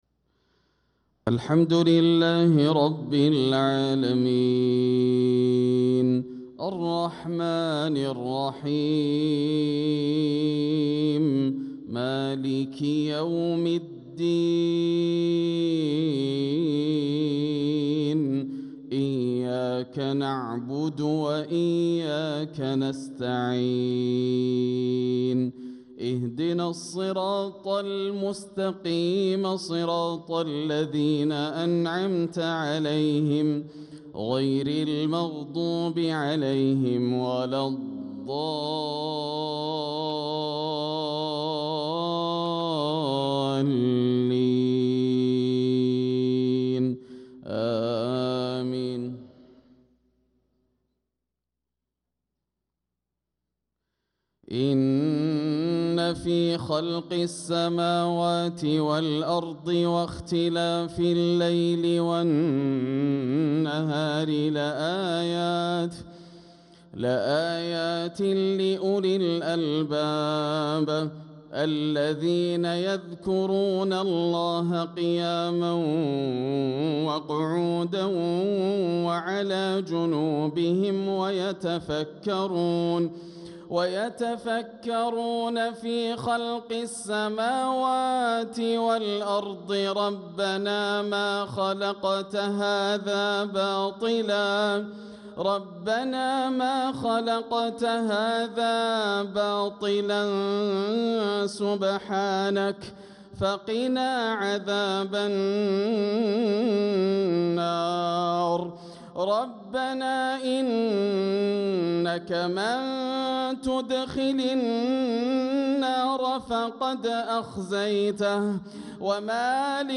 صلاة العشاء للقارئ ياسر الدوسري 18 ربيع الآخر 1446 هـ
تِلَاوَات الْحَرَمَيْن .